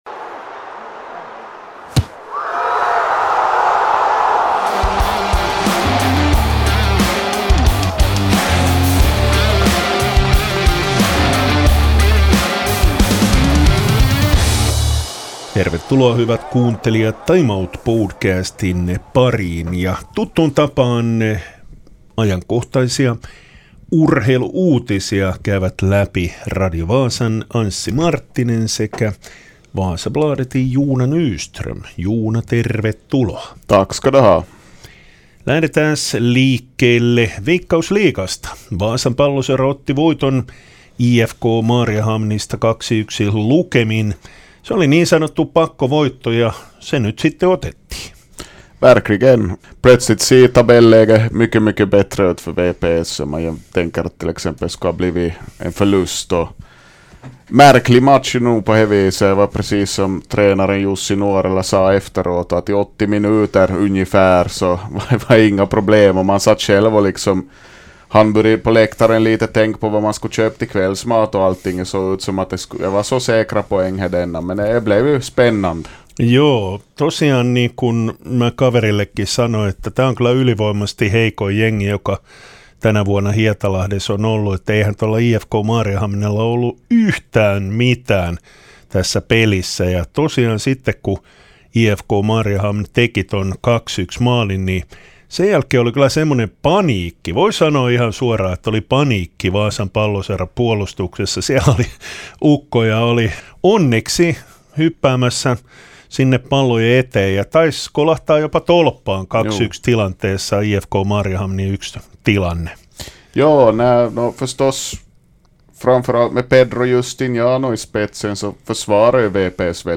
I studion